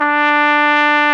BRS CORNET03.wav